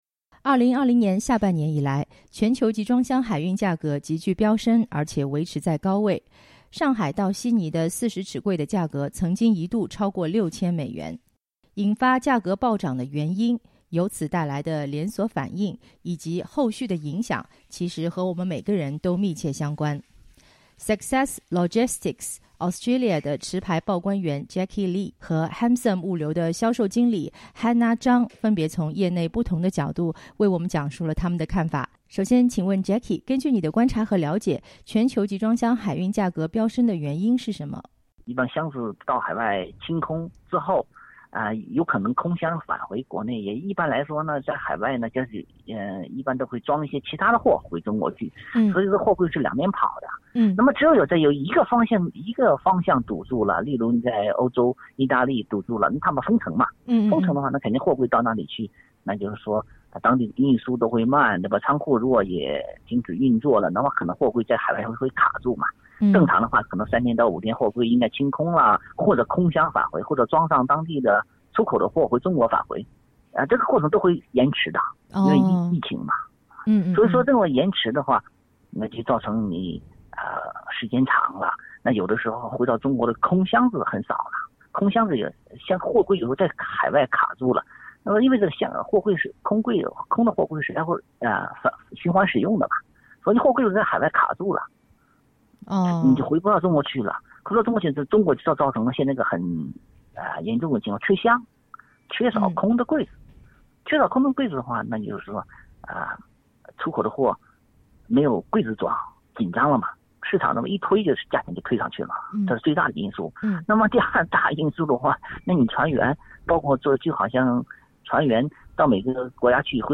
(点击封面图片收听完整音频报道)